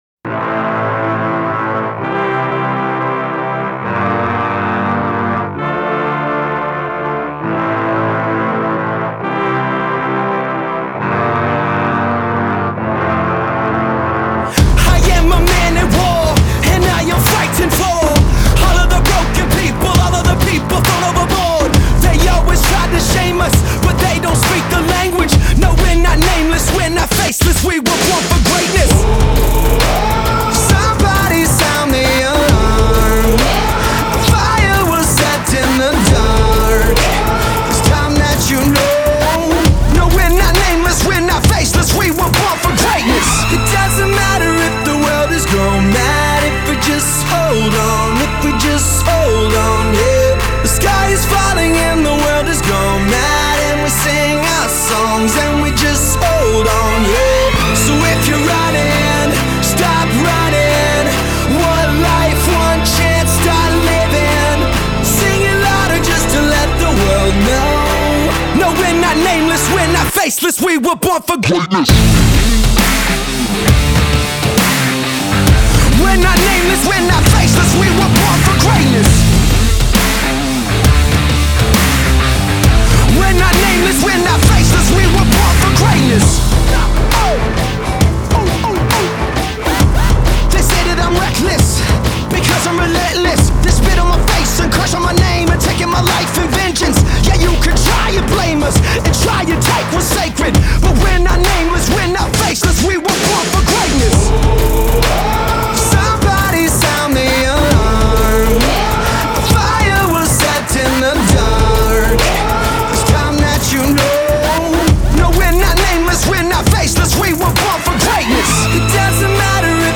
Genre : Alternative Rock